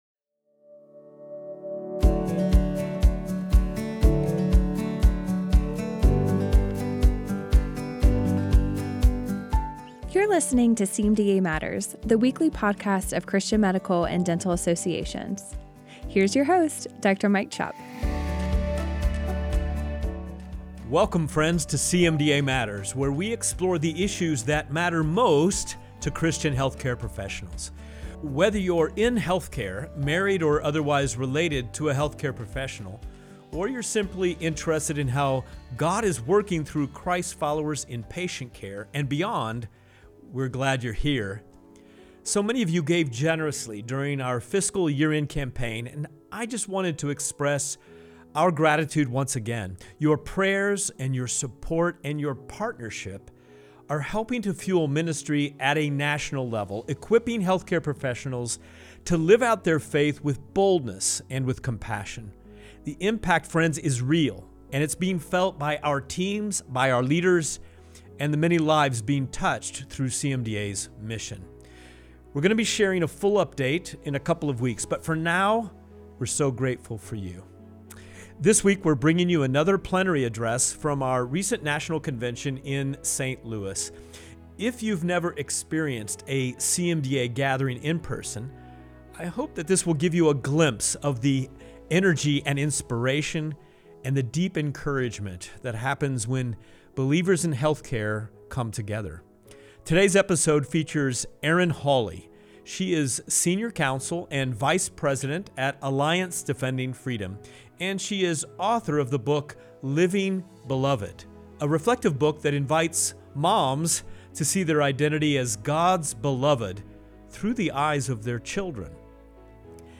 This week on CMDA Matters, we bring you a powerful plenary address from our National Convention in St. Louis, featuring Erin Hawley, Senior Counsel and Vice President at Alliance Defending Freedom. A key figure in the landmark Dobbs v. Jackson Women’s Health Organization case and lead counsel in Alliance for Hippocratic Medicine v. FDA, Erin shares a compelling behind-the-scenes look at the legal battles shaping the future of life and conscience in America. With clarity and conviction, she highlights CMDA’s ongoing role in defending the unborn, protecting pregnancy centers, and standing for truth in a post-Roe world.